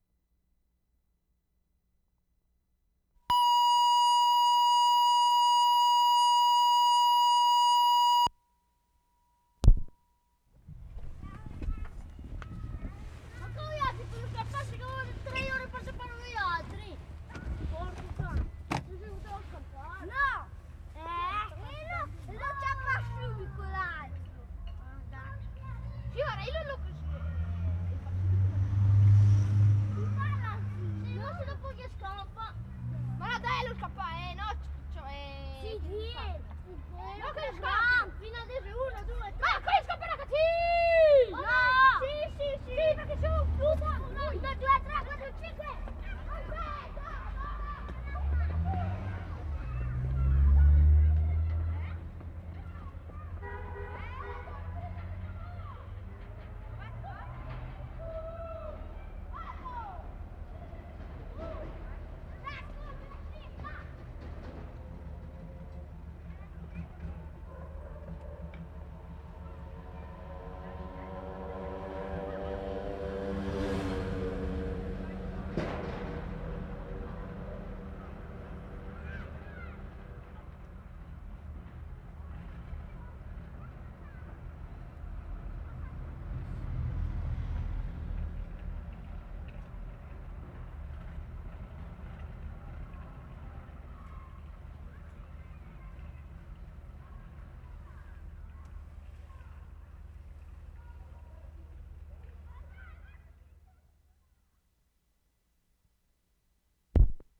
Cembra, Italy March 28/75
CHILDREN PLAYING on main street.
6. Note cadence of voices here.